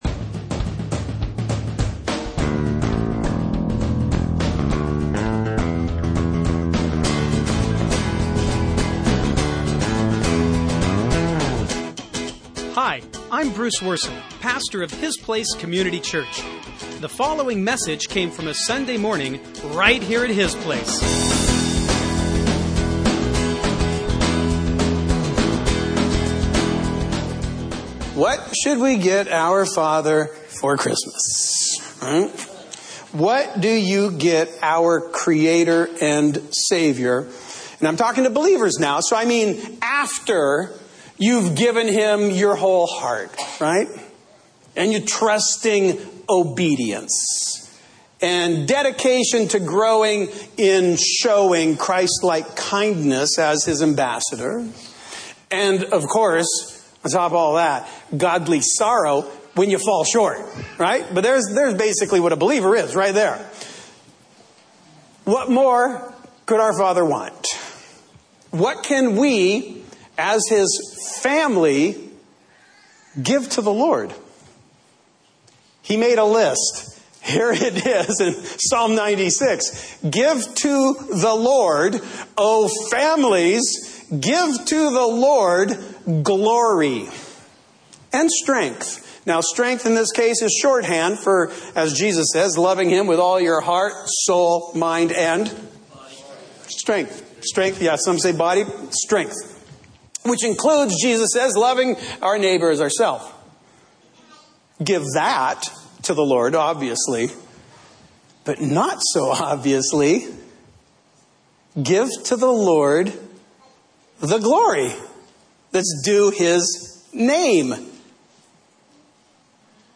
Christmas Sermon